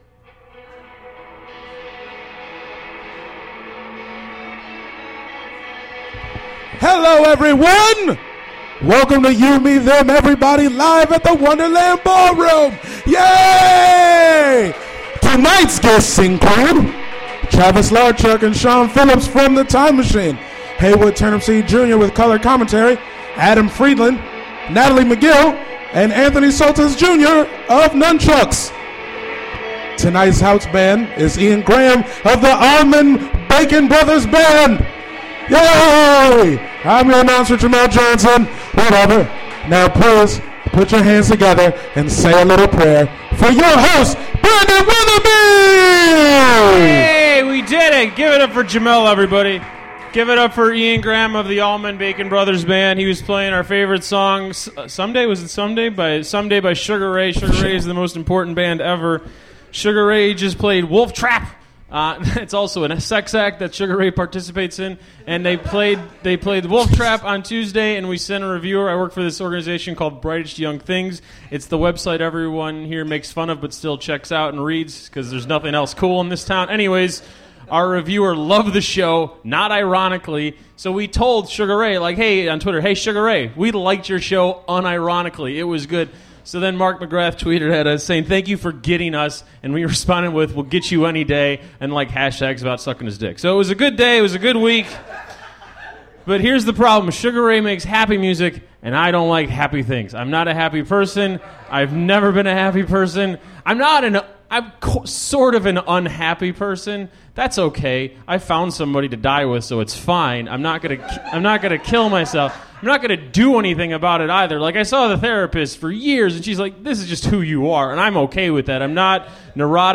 Live at the Wonderland Ballroom